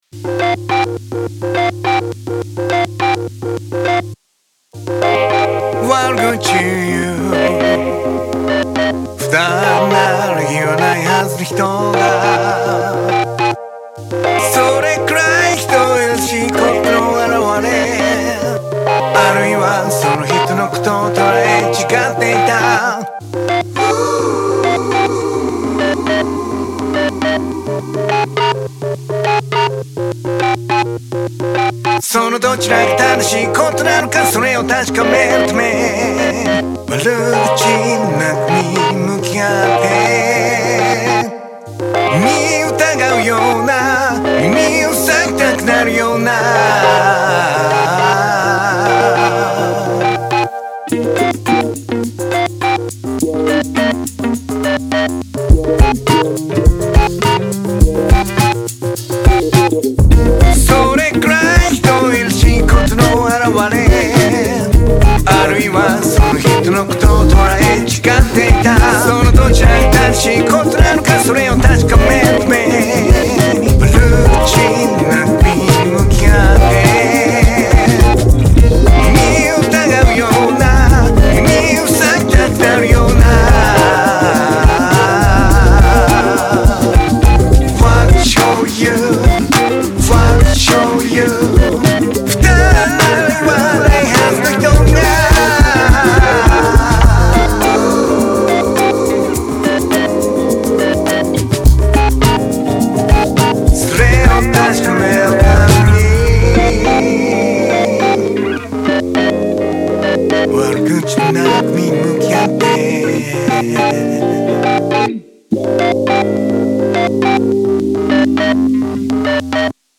毎日1曲、新曲つくってアレンジ＆録音したものを日々アップロード中。